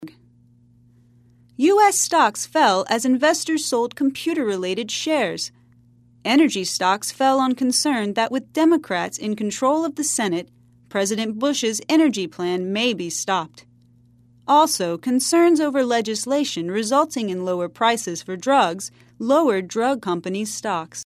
在线英语听力室赖世雄英语新闻听力通 第25期:美国股票大跌的听力文件下载,本栏目网络全球各类趣味新闻，并为大家提供原声朗读与对应双语字幕，篇幅虽然精短，词汇量却足够丰富，是各层次英语学习者学习实用听力、口语的精品资源。